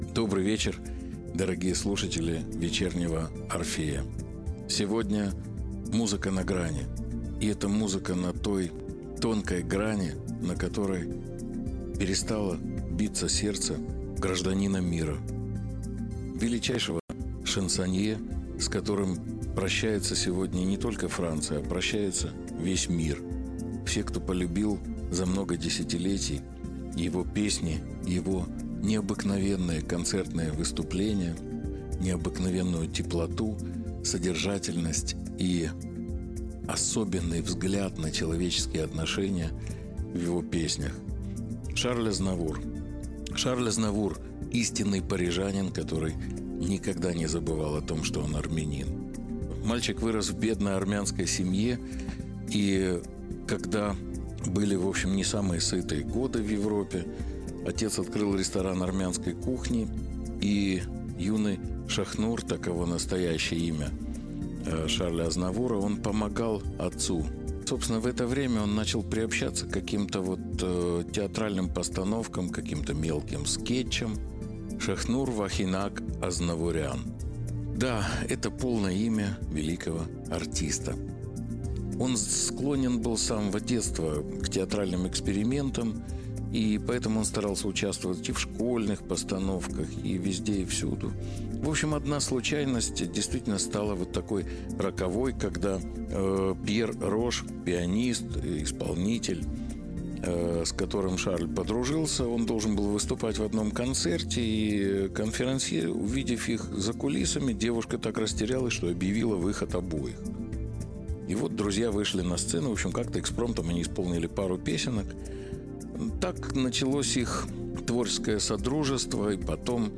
концерт в Карнеги-холл.